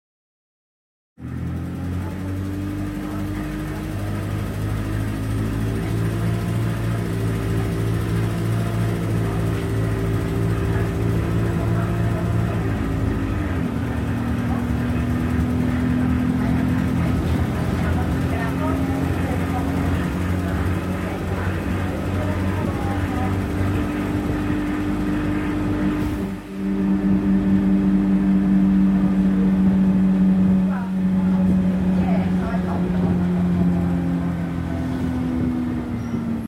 YX14 RUU absolutely love the new livery and the interior but the bus sounds a bit awful 😭